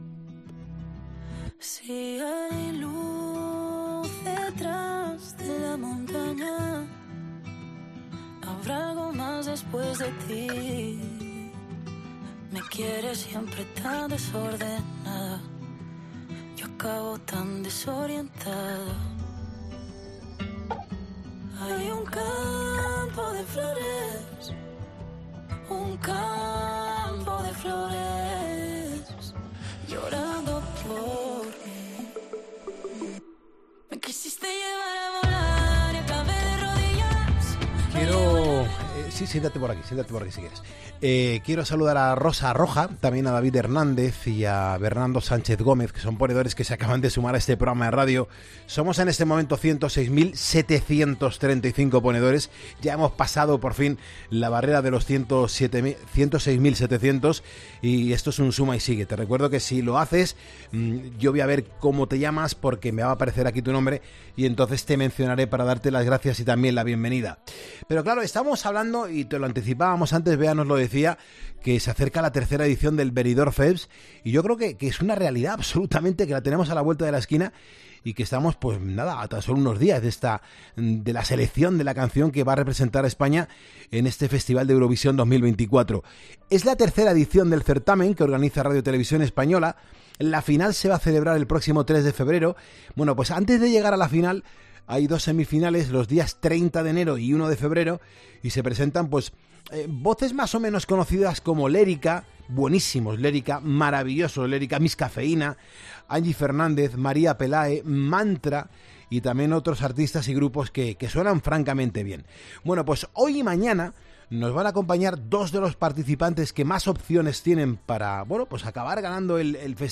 Yoly Saa ha estado en el estudio de Poniendo las Calles y le ha confesado a Carlos Moreno "El Pulpo" que el Benidorm Fest es una gran oportunidad para...